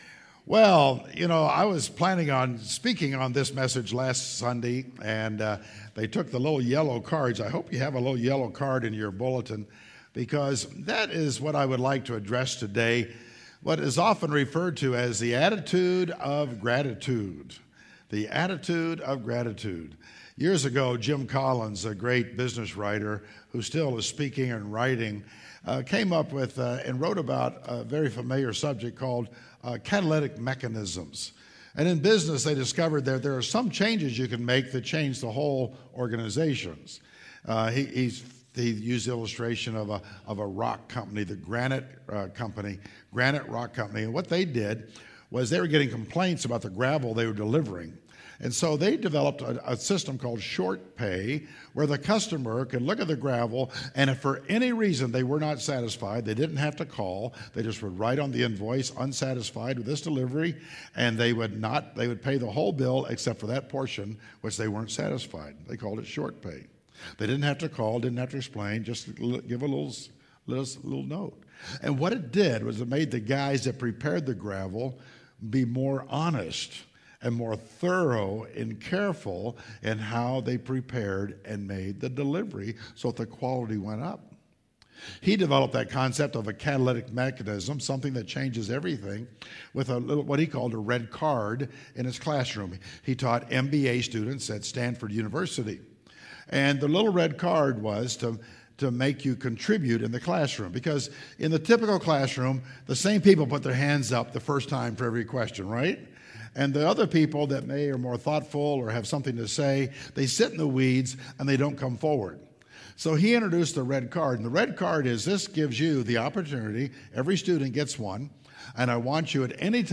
Sermonss